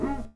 Vox 4 (WOAH).wav